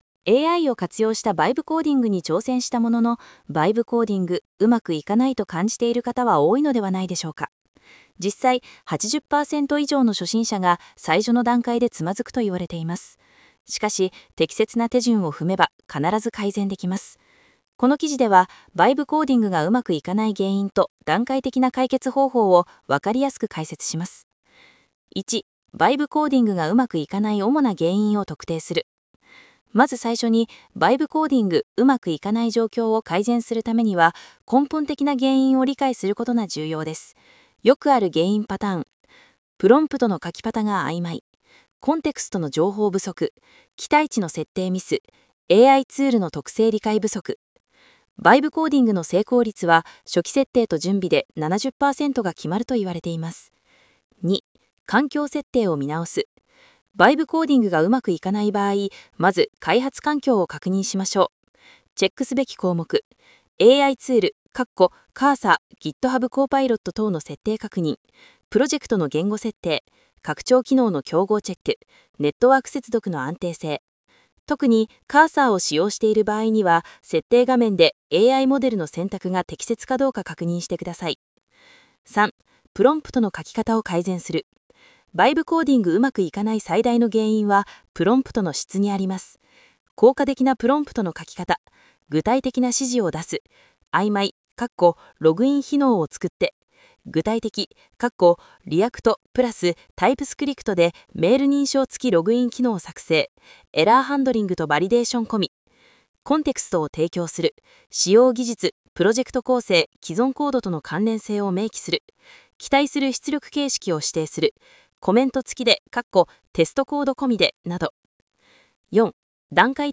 記事の読み上げ